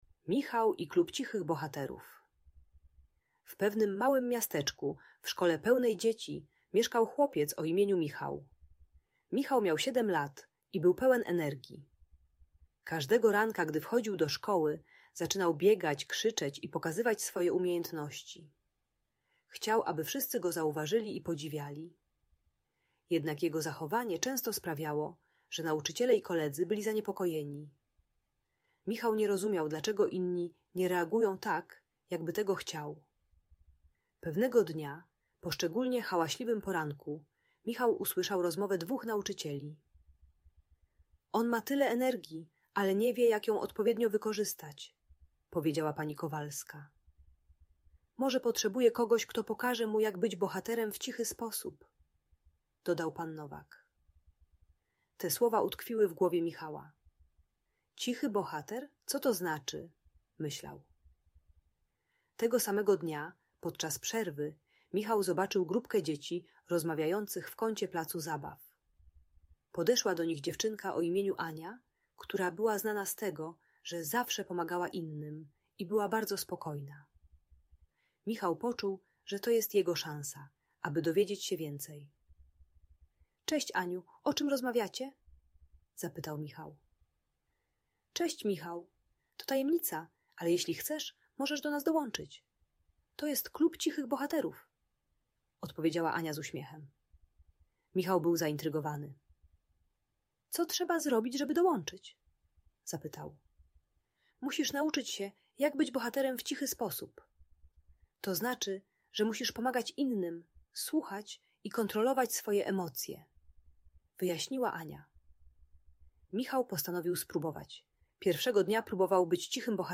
Historia Michała uczy, jak kontrolować emocje i zdobywać pozytywną uwagę poprzez ciche pomaganie innym. Audiobajka o nadpobudliwości i szukaniu akceptacji rówieśników.